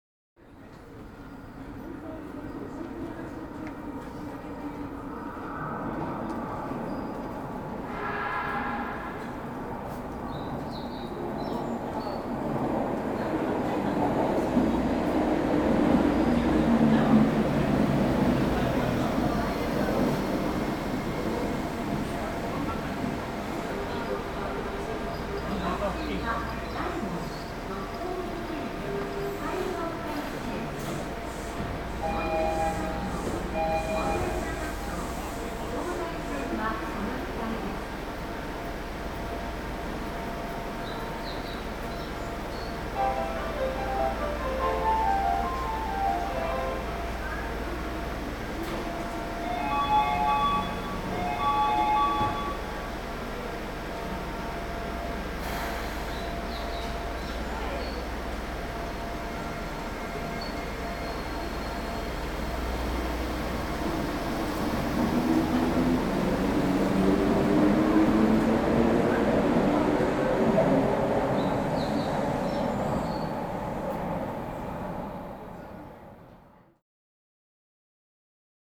機械のモーター音やアナウンス、発車の合図......。地下鉄、都電荒川線、都営バスのそれぞれの場所でしか聞くことができない音を収録しました。
第8回地下鉄大江戸線「ホーム音」
第8回 地下鉄大江戸線「ホーム音」 門前仲町駅で収録した、列車の接近音とホームドアの開閉音。